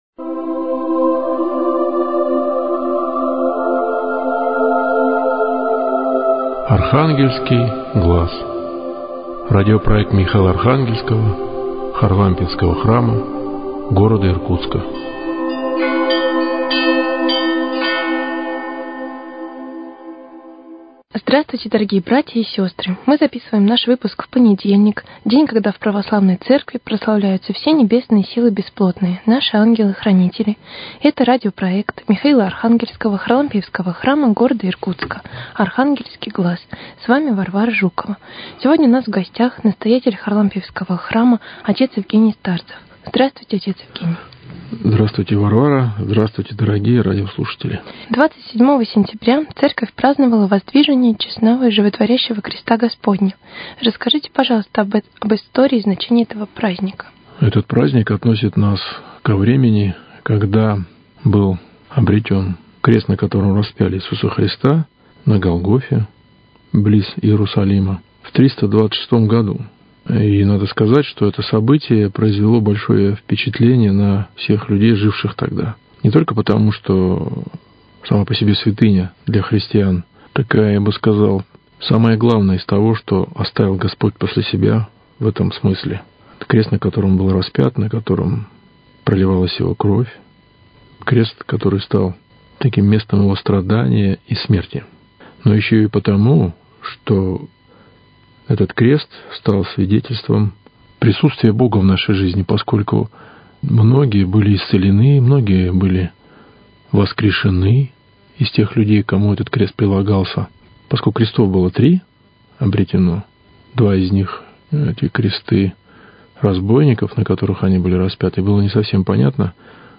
Беседа об истории и значении праздника. Есть ли в Церкви «День пожилого человека», и как христиане относятся к родителям? Почему священник служит Литургию, даже если в храме никого кроме него нет?